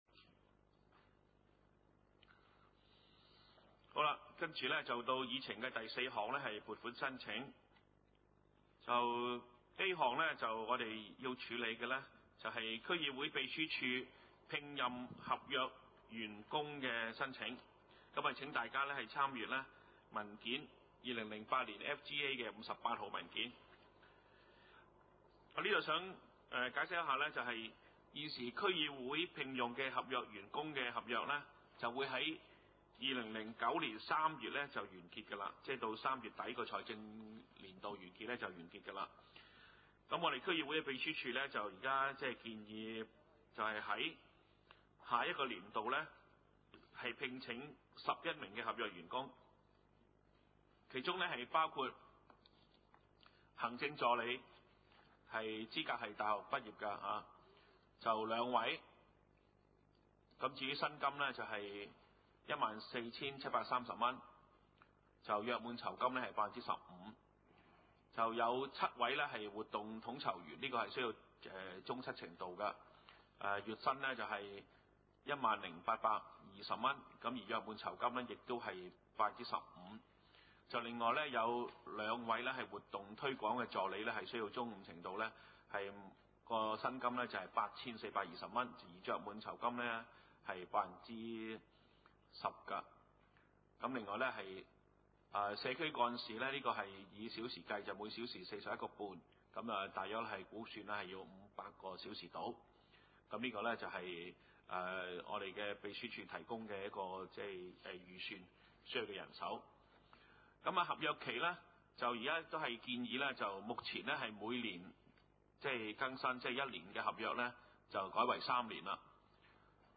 : 沙田區議會會議室